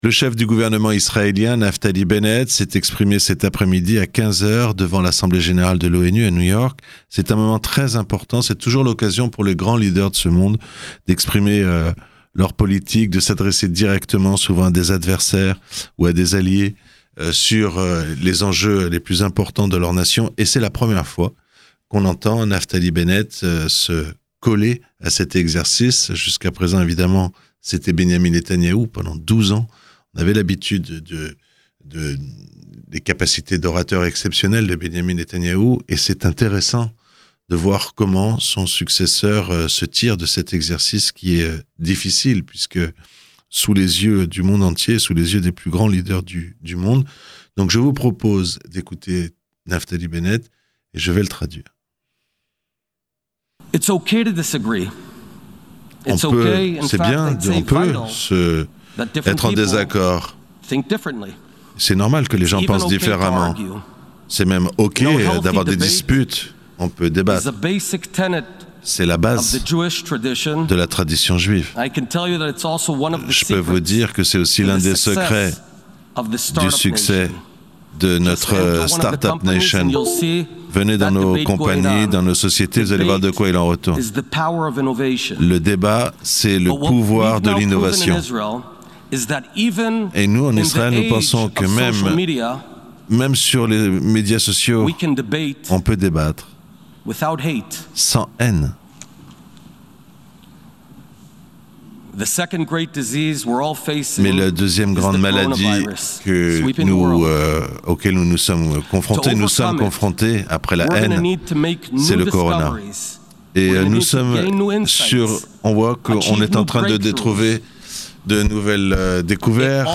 Discours de Naftali Benett devant l'Assemblée Générale de l'Onu traduit en français
Le chef du gouvernement israélien s'est exprimé devznt l'Assemblée Générale de l'Onu.